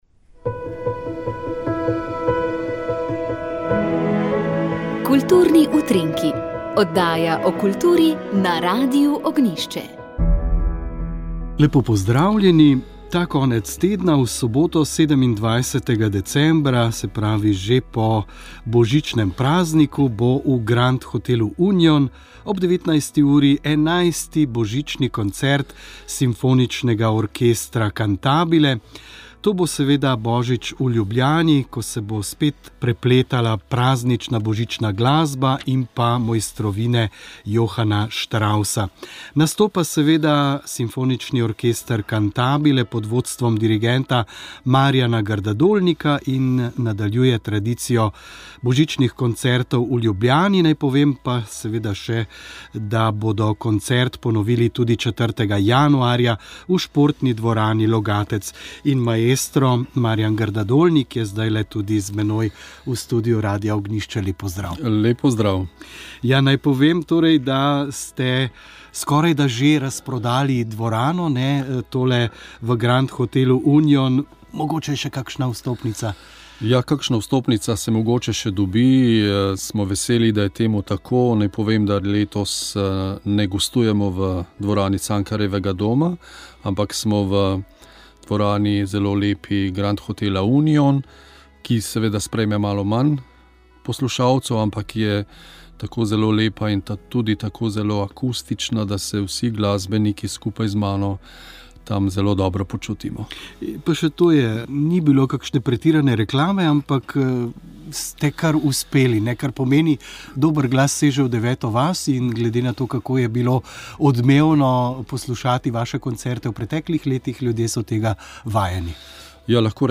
Odgovorila je tudi na vprašanja poslušalcev, povezana s pogrebi (o glasnem govorjenju, oblekah, klobukih pri uniformiranih osebah …).